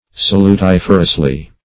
Salutiferously \Sal`u*tif"er*ous*ly\, adv.
salutiferously.mp3